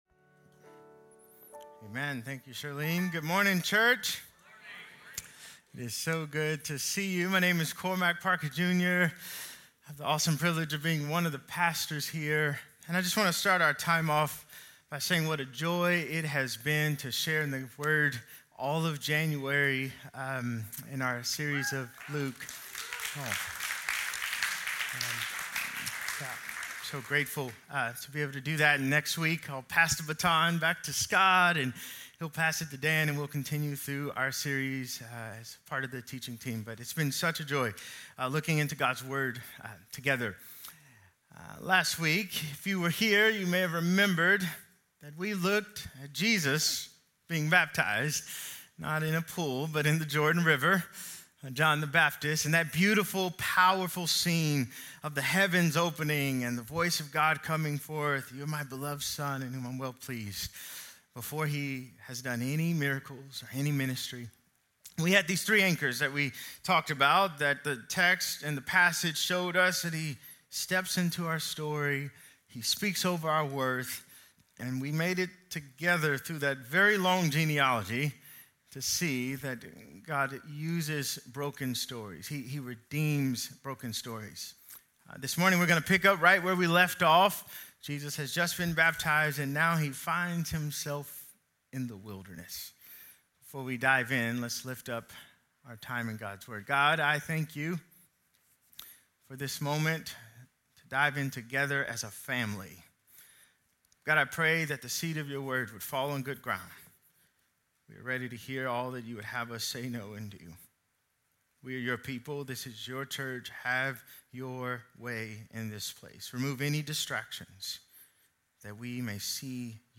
Sermons - Peninsula Bible Church